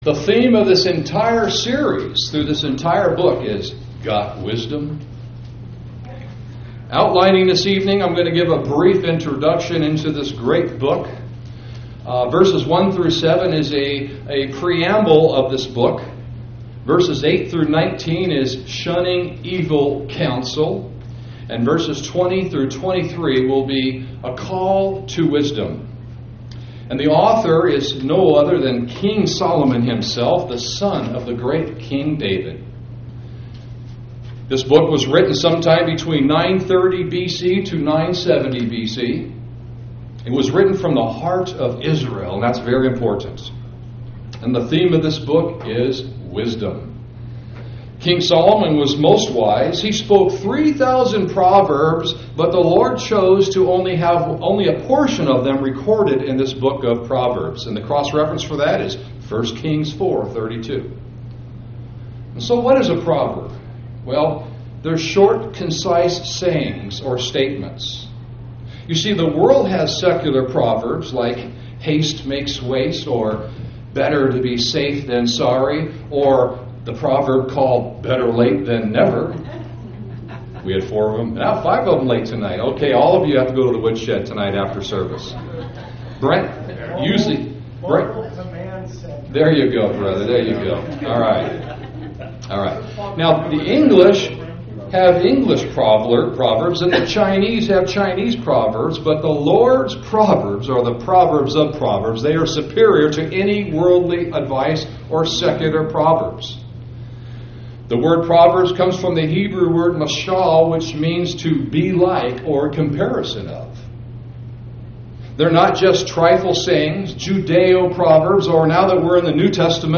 Sermon audio on Proverbs Chapter 1 (was told this message was “Orthodox Christianity”)